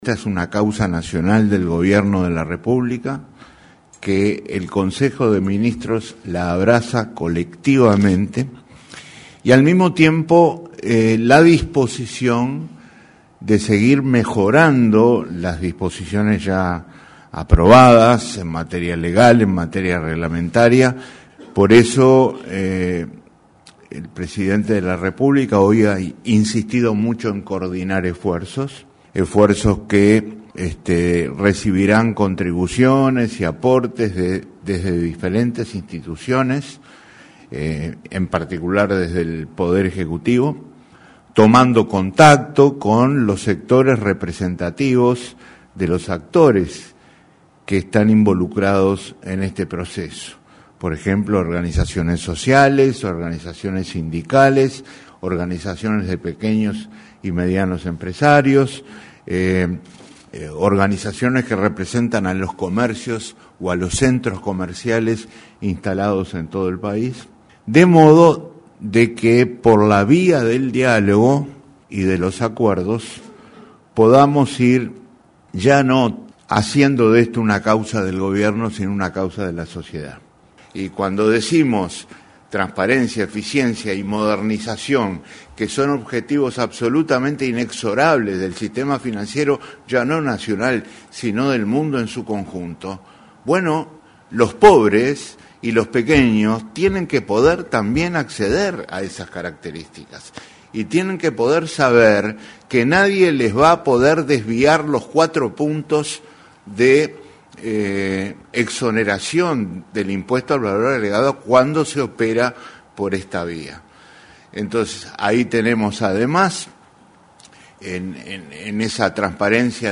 “El gobierno decidió impulsar colectivamente la Ley de Inclusión Financiera y coordinar esfuerzos para aplicarla y mejorarla”, afirmó el ministro de Economía, Danilo Astori, tras el Consejo de Ministros.